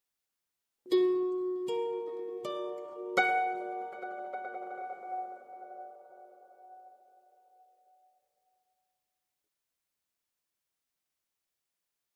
Harp, Arpeggio Reverberant Accent, Type 2 - Minor 7th